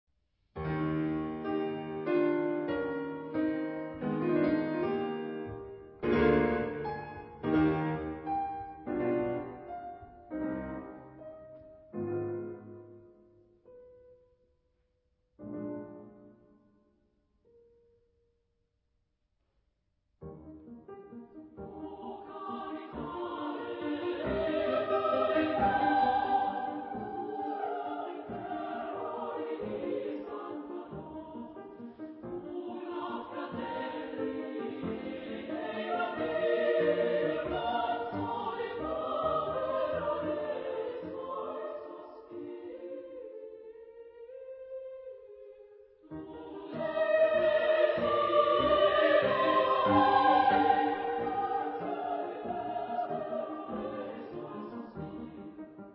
Genre-Style-Form: Romantic ; Lied
Type of Choir: SSA  (3 women voices )
Soloist(s): Soprano (1)  (1 soloist(s))
Instruments: Piano (1)
Tonality: E flat major